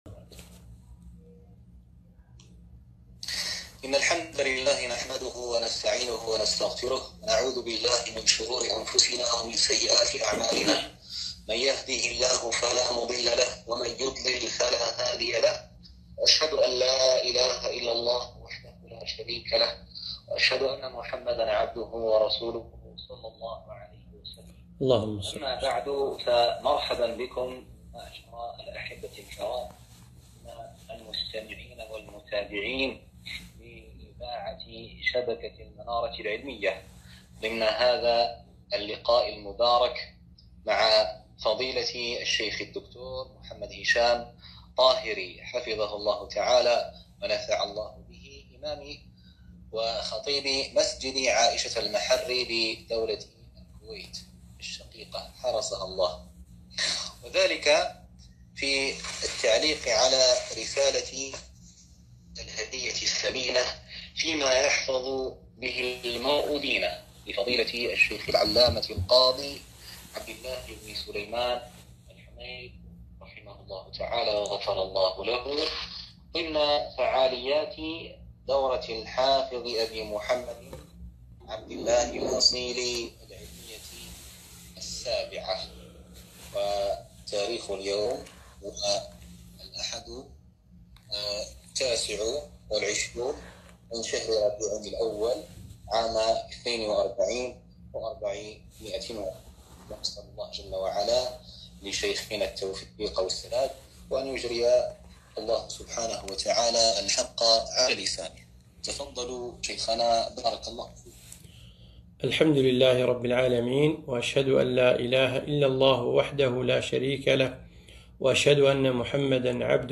محاضرة - الهدية الثمينة فيما يحفظ به المرء دينه